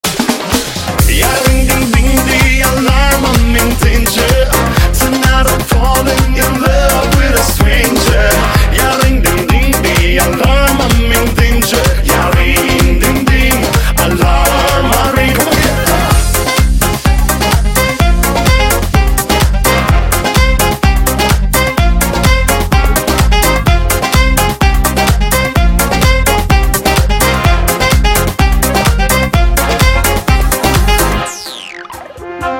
• Качество: 160, Stereo